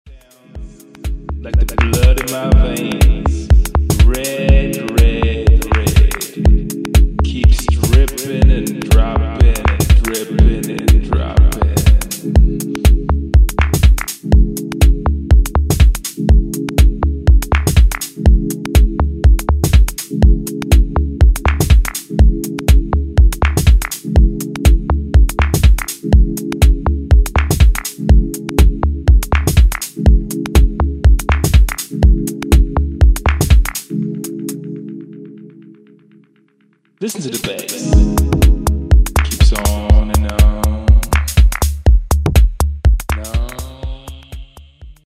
Clean and fresh modern house tracks
House